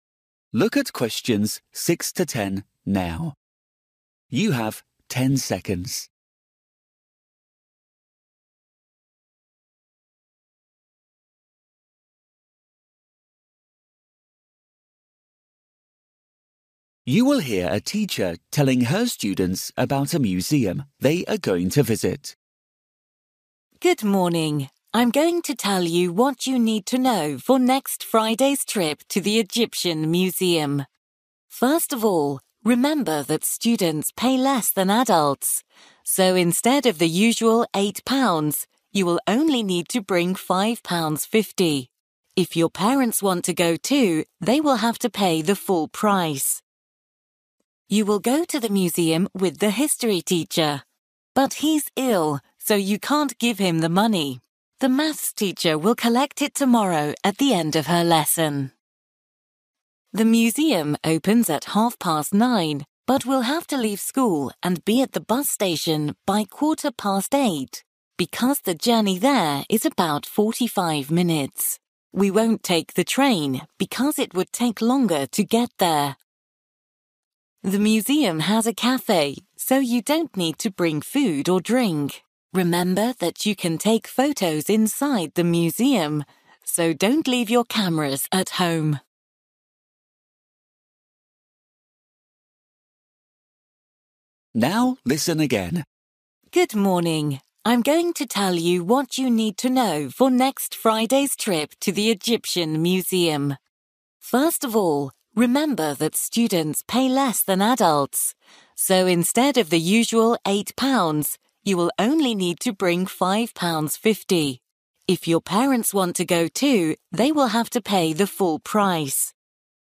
You will hear a teacher telling her students about a museum they are going to visit.